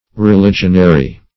Meaning of religionary. religionary synonyms, pronunciation, spelling and more from Free Dictionary.